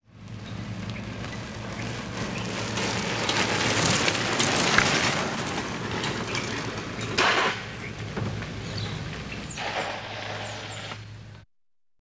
Звуки велосипеда
Звук велосипеда пролетающего рядом